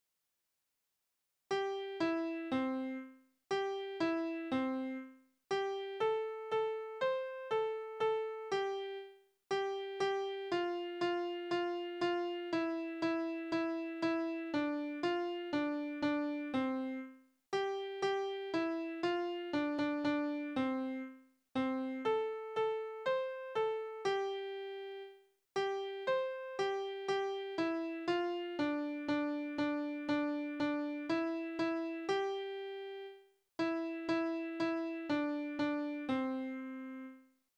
Tonart: C-Dur
Taktart: 4/4
Tonumfang: Oktave
Besetzung: vokal